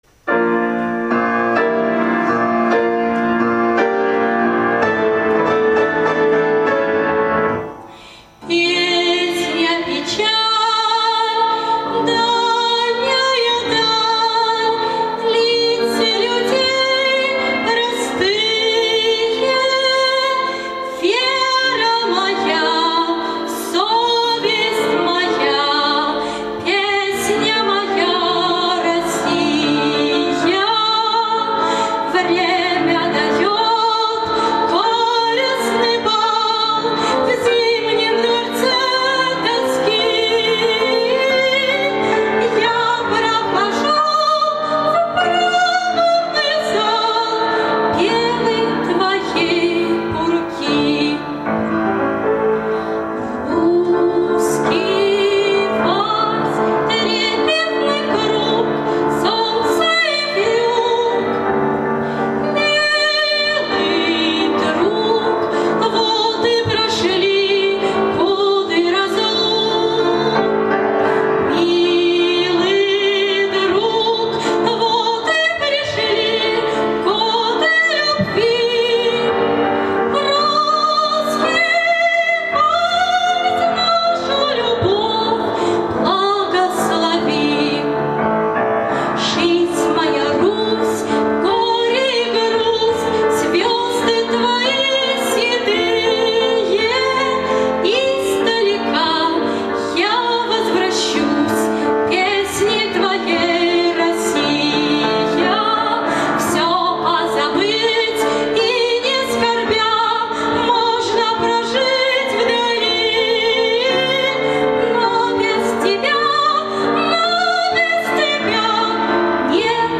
Живой звук